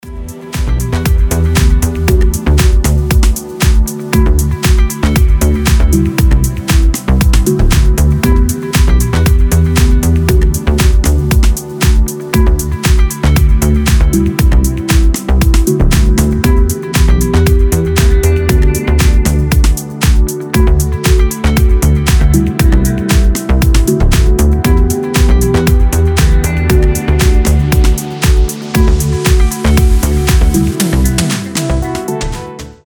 спокойные
без слов
chillout
расслабляющие
Lounge